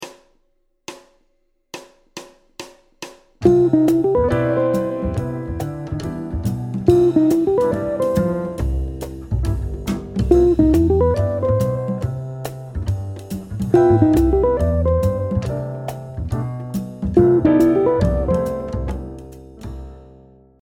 C Lancement par un chromatisme d’un arpège substitutif à C ∆.